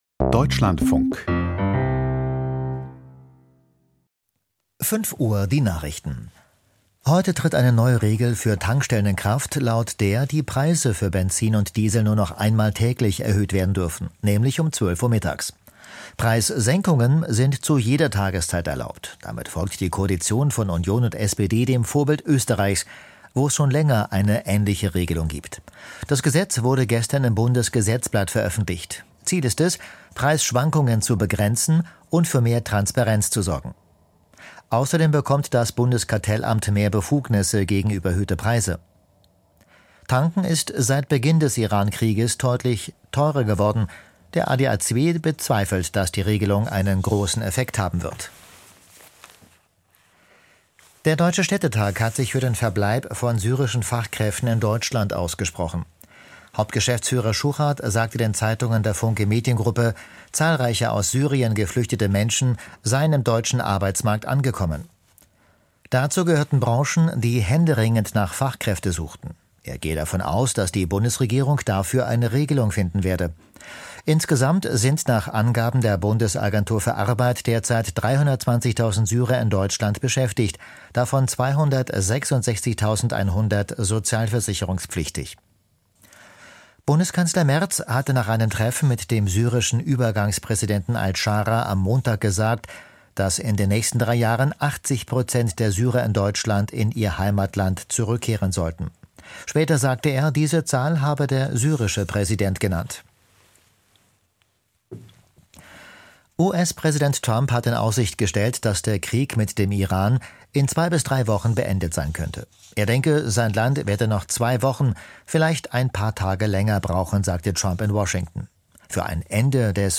Die Nachrichten vom 01.04.2026, 05:00 Uhr